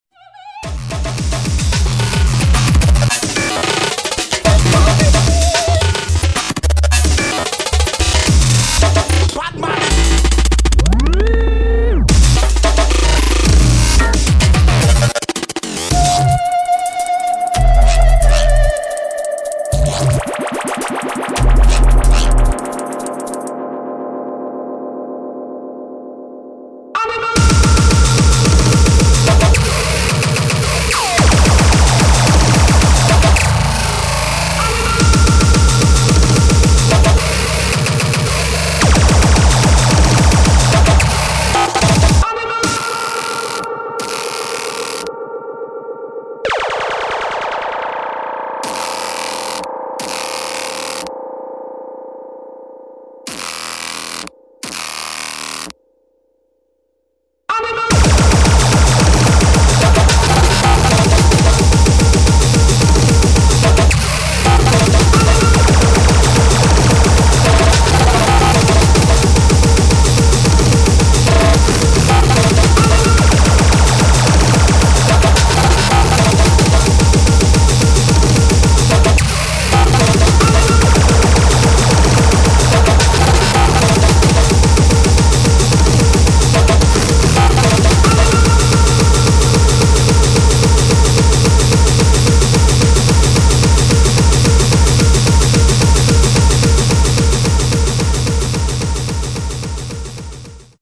[ DRUM'N'BASS / HARDCORE / BREAKCORE ]